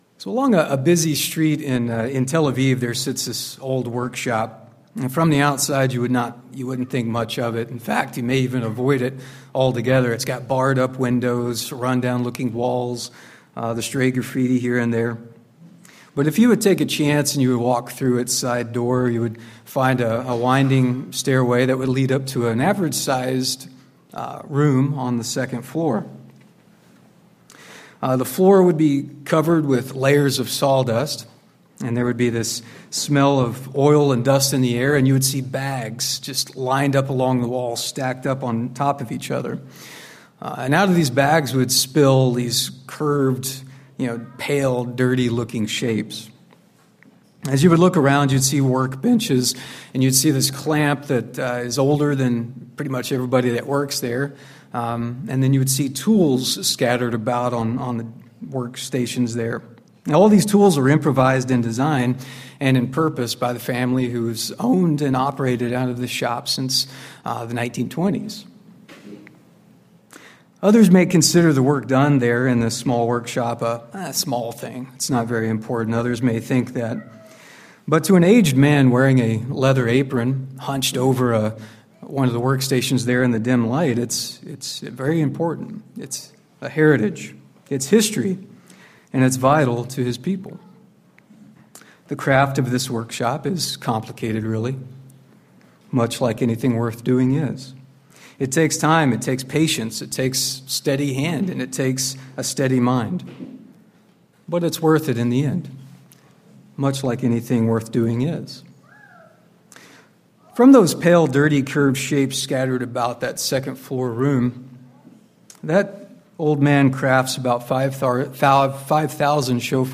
Given in Spokane, WA Chewelah, WA Kennewick, WA